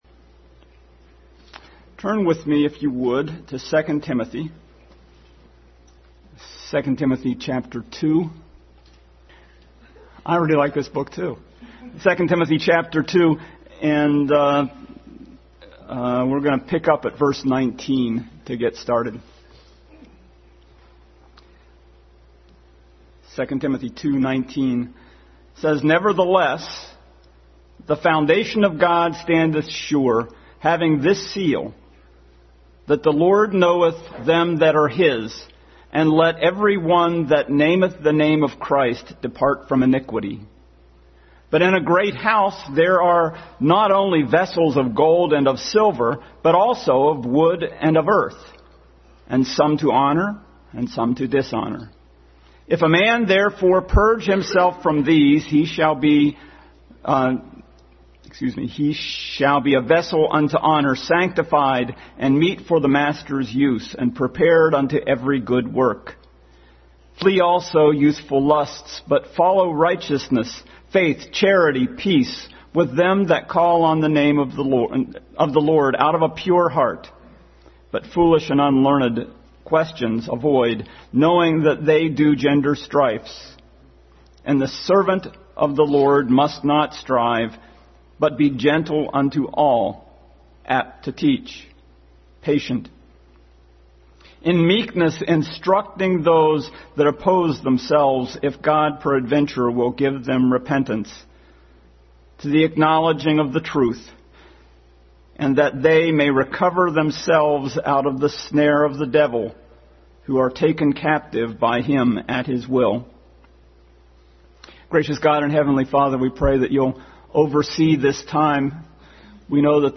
Passage: 2 Timothy 2:19-26, 3:16 Service Type: Sunday School